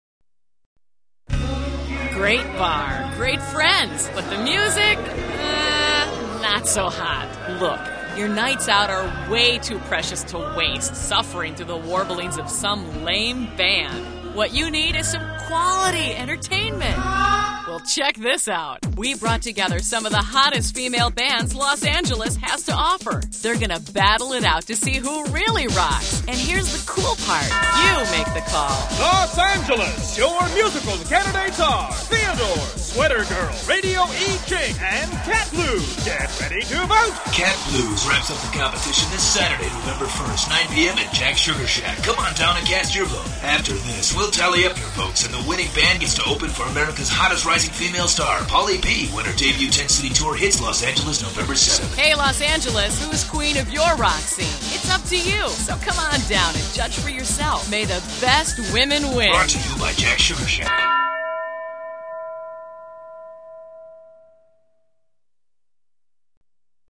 Battle of the Female Bands Radio Commercial